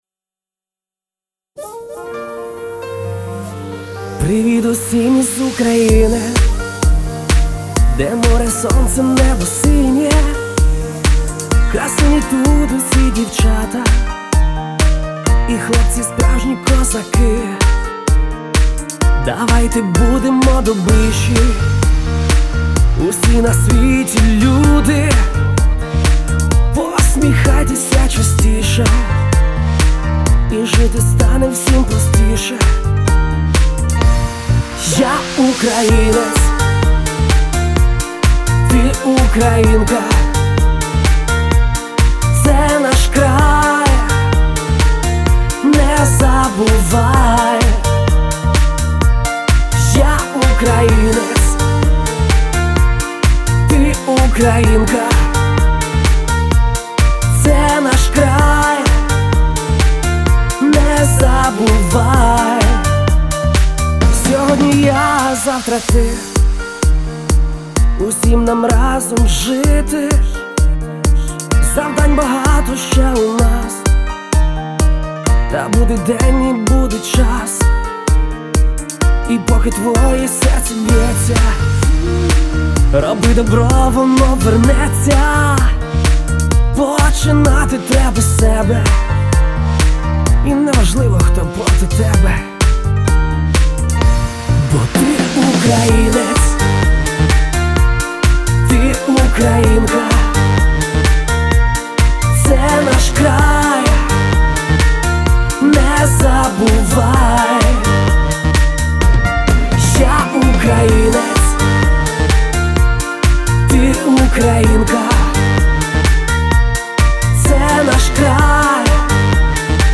Всі мінусовки жанру Pop
Плюсовий запис